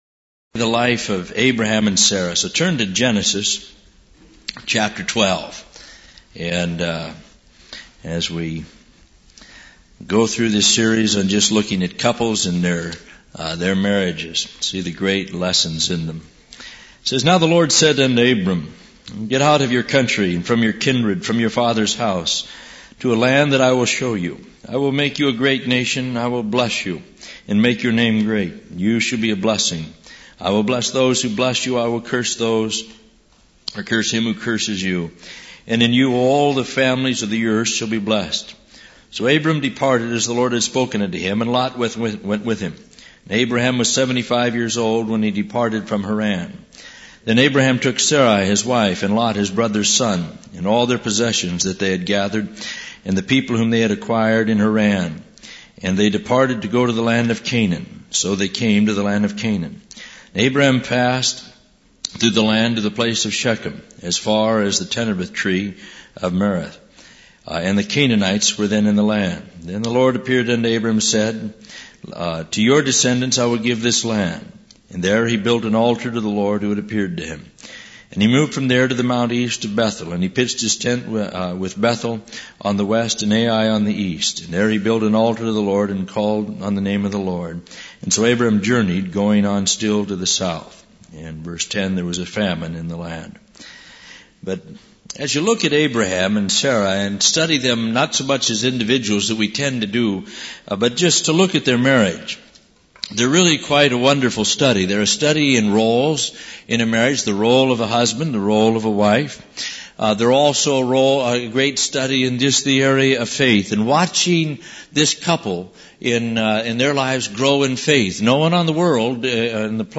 In this sermon, the preacher focuses on the story of Abraham and Isaac from Genesis 22.